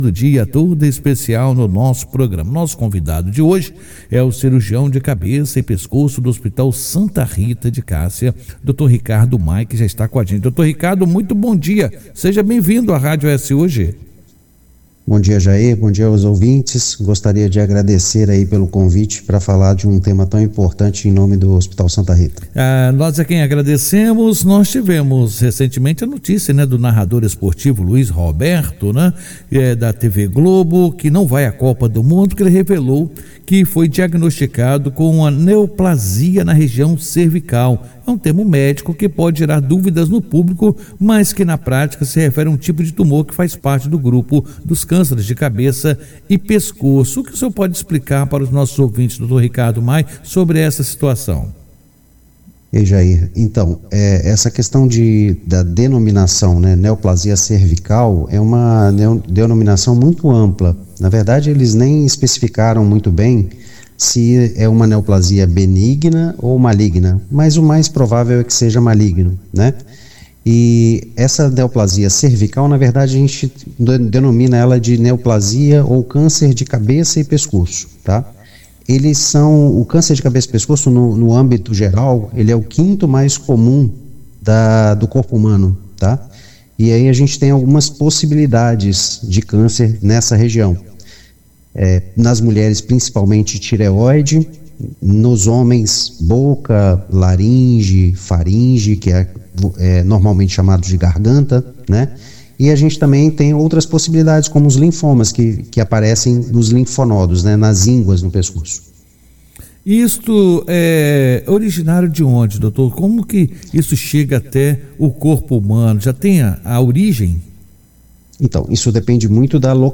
Em entrevista à Rádio ES Hoje o cirurgião de cabeça e pescoço